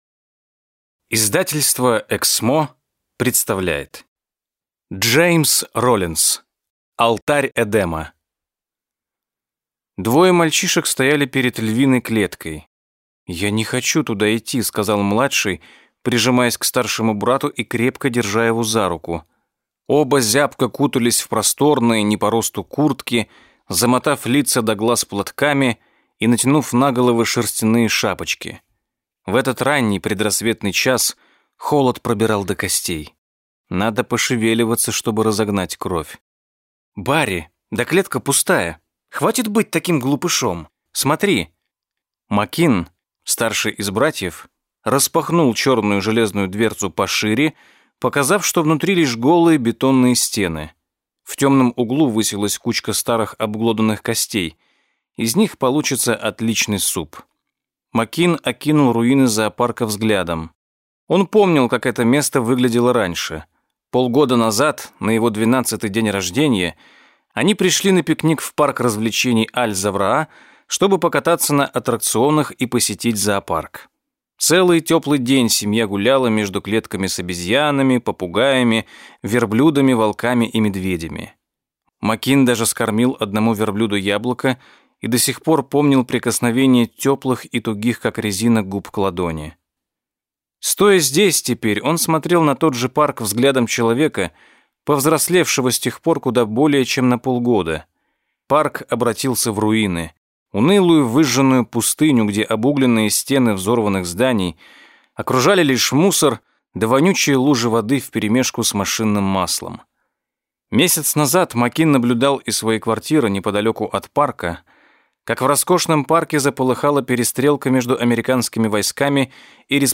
Аудиокнига Алтарь Эдема | Библиотека аудиокниг